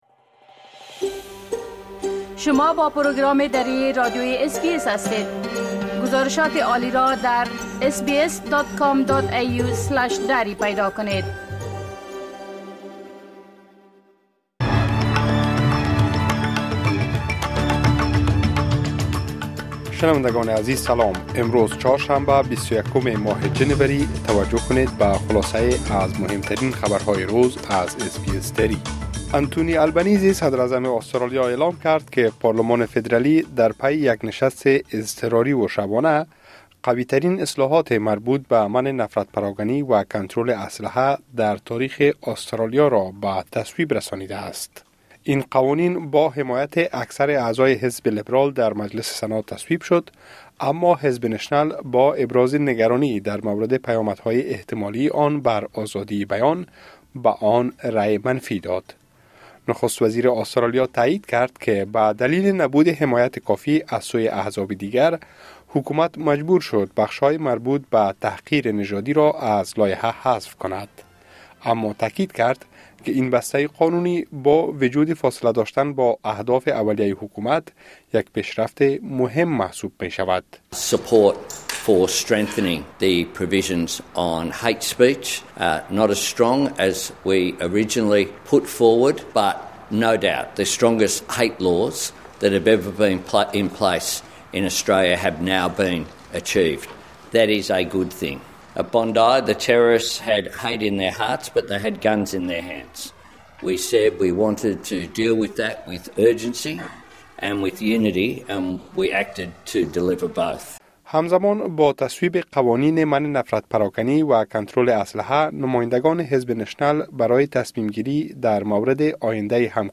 خلاصه‌ای مهم‌ترين خبرهای روز | ۲۱ جنوری